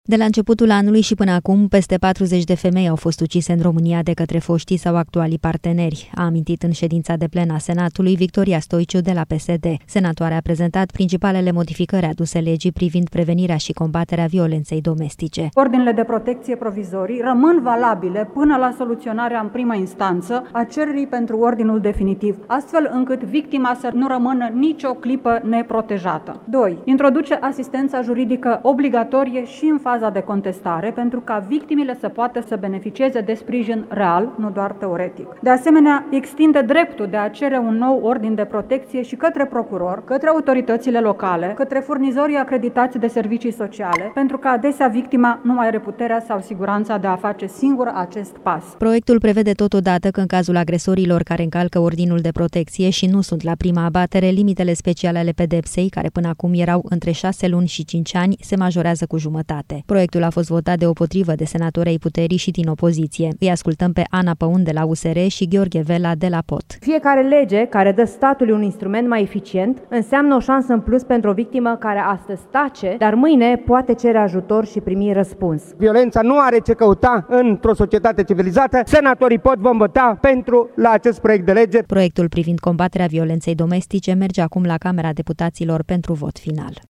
De la începutul anului și până acum, peste 40 de femei au fost ucise în România de către foștii sau actualii parteneri, a spus, în ședința de plen a Senatului, Victoria Stoiciu de la PSD.